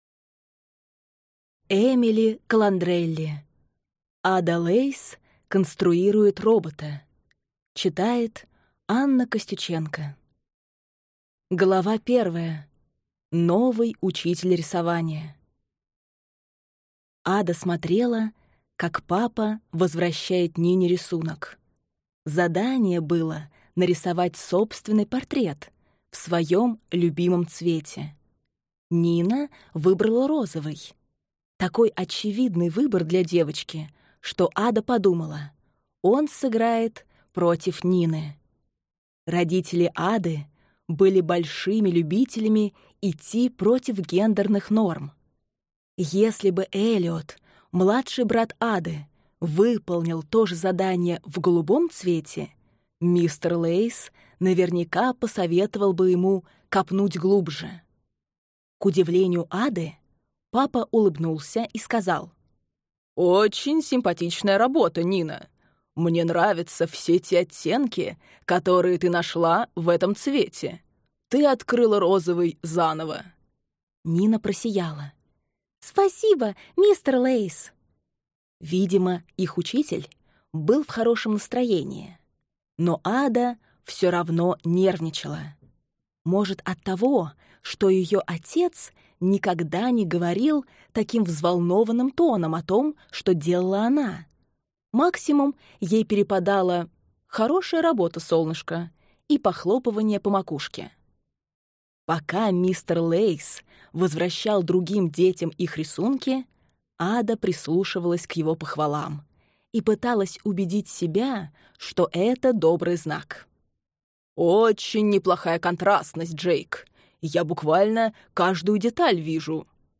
Аудиокнига Ада Лейс конструирует робота | Библиотека аудиокниг